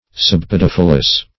Search Result for " subpodophyllous" : The Collaborative International Dictionary of English v.0.48: Subpodophyllous \Sub*pod`o*phyl"lous\, a. (Anat.)